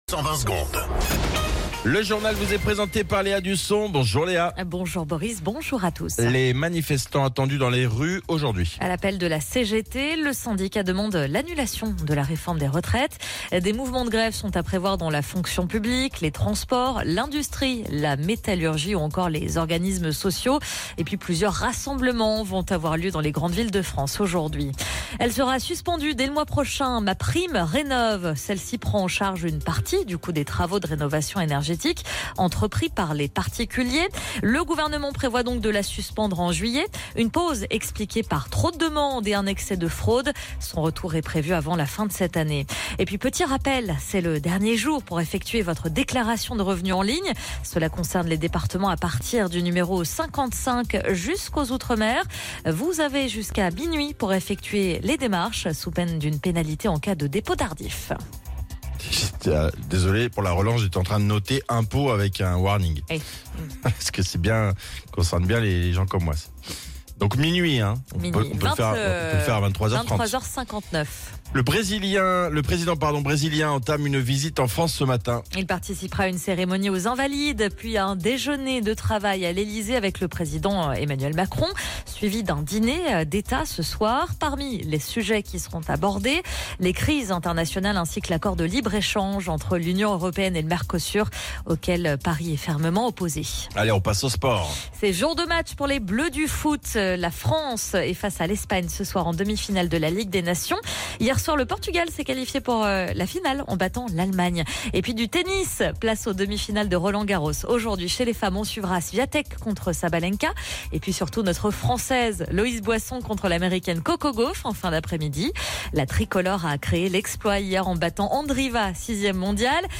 Flash Info National 05 Juin 2025 Du 05/06/2025 à 07h10 .